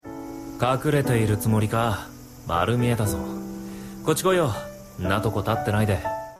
Semuanya diambil dari anime CLANNAD AFTER STORY.
Dialog di atas juga pembicaraan ayah ke anaknya. nna merupakan singkatan sonna (seperti itu).